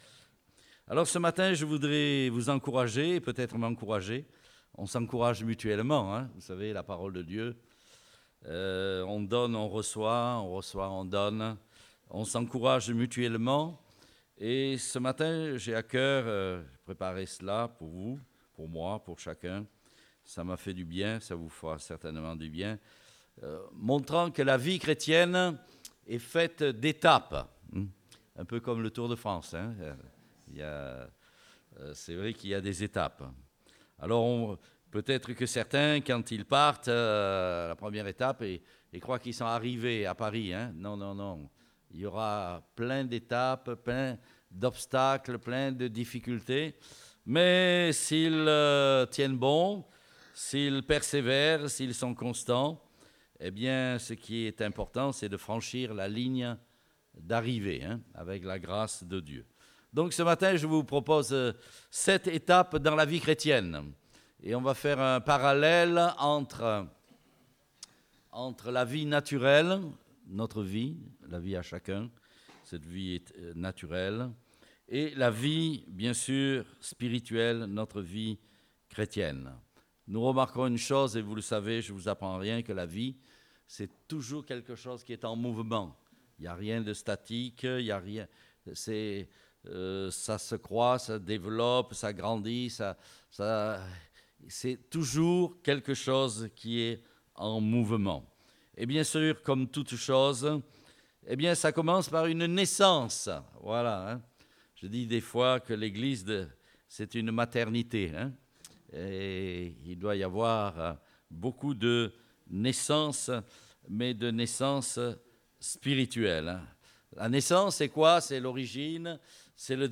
Date : 8 juillet 2018 (Culte Dominical)